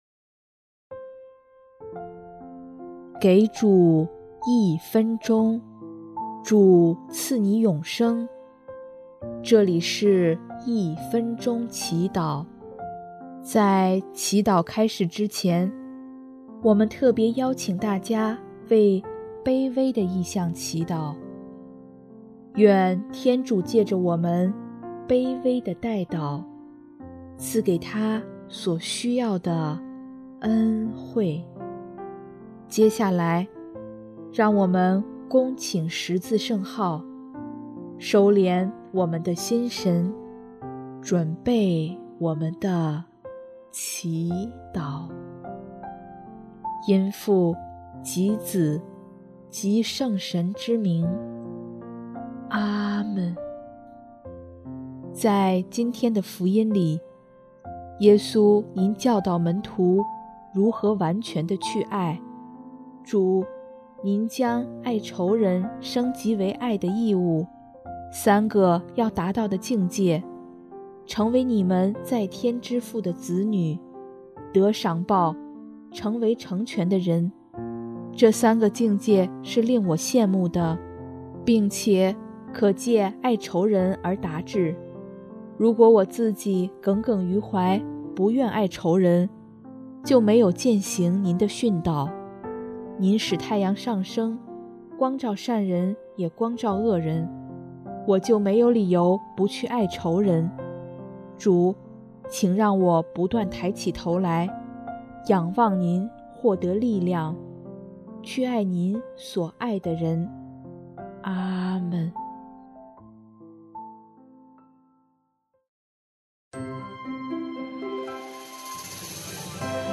音乐：第四届华语圣歌大赛参赛歌曲《别无所求》